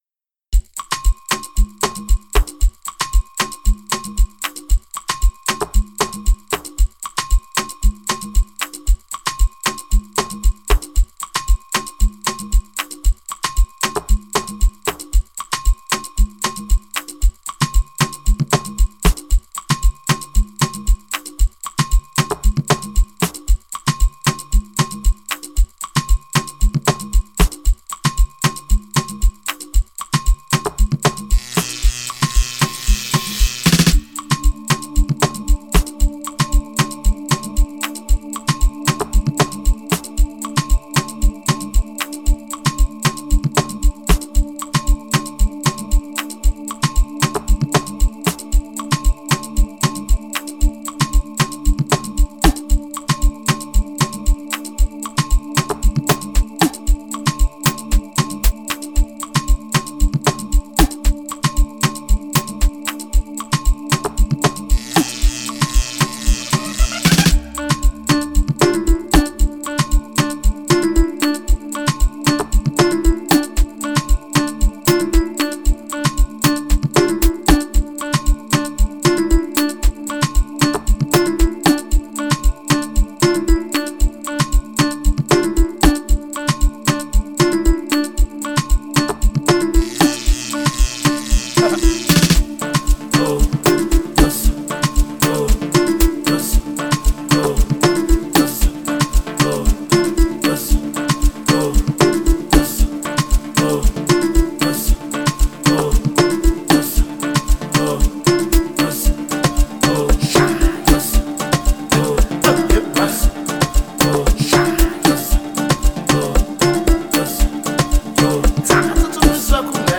Genre: Afro-House